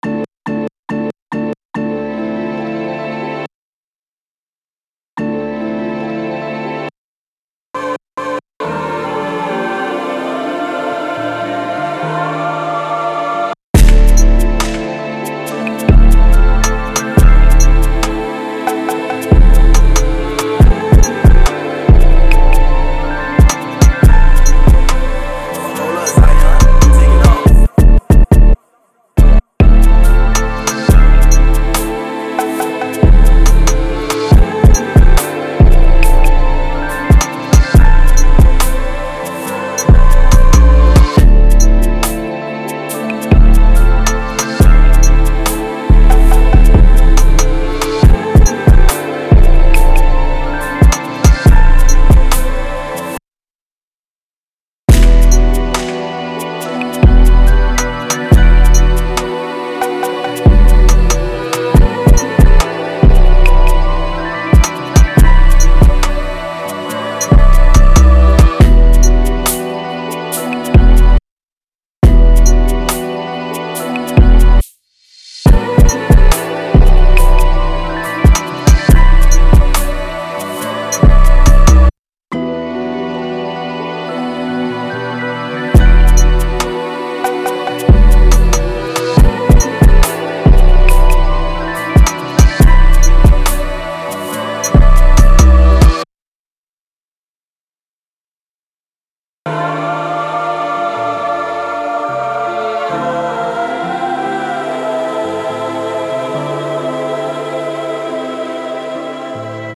Drill
140 A Minor